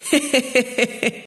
voz nș 0159